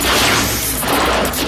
machine_idle_3star_01.ogg